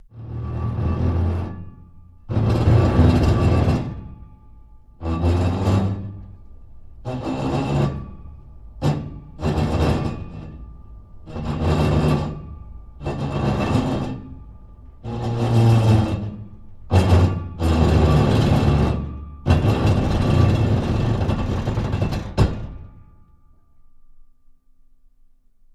Car Transmission Manual; Starts Rolling And Goes Steady With Very Bad Gear Grinds, Winds Down To Stop At End, No Motor, Interior Perspective 4x